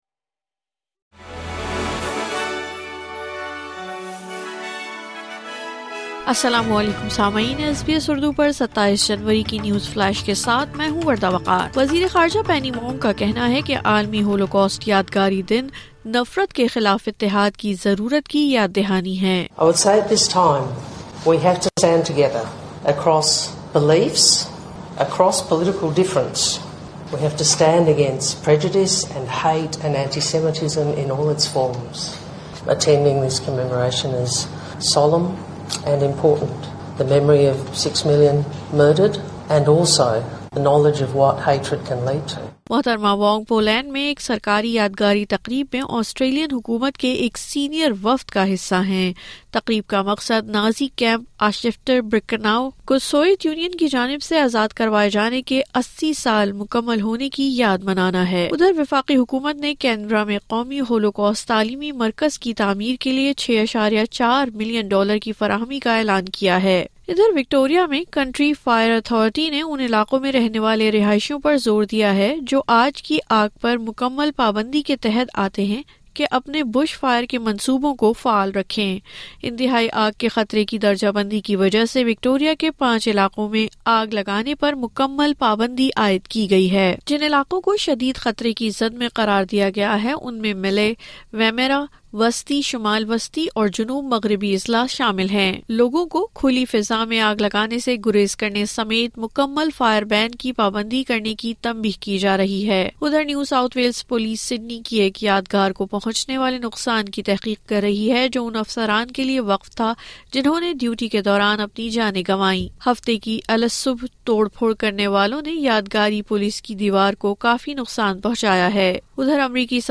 نیوز فلیش :27 جنوری 2025